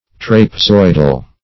Search Result for " trapezoidal" : Wordnet 3.0 ADJECTIVE (1) 1. resembling a trapezoid ; The Collaborative International Dictionary of English v.0.48: Trapezoidal \Trap`e*zoid"al\, a. [Cf. F. trap['e]zo["i]dal.]